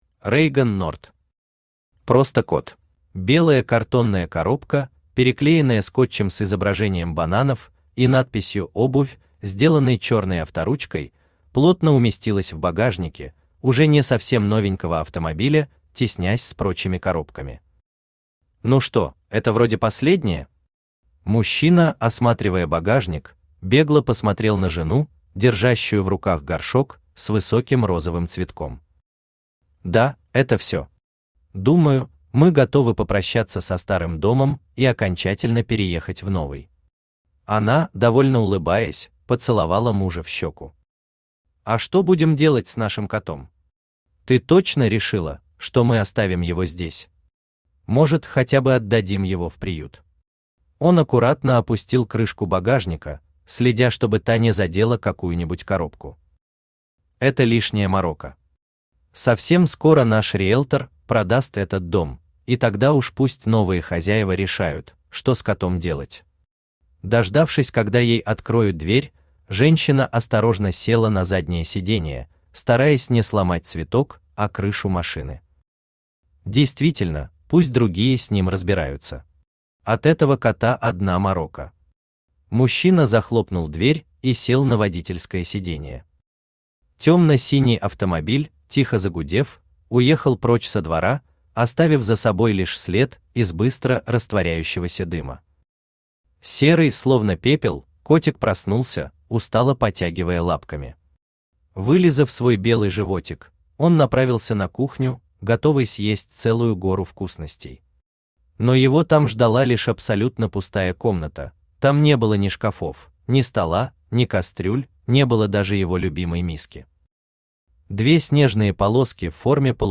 Aудиокнига Просто Кот Автор Reigon Nort Читает аудиокнигу Авточтец ЛитРес.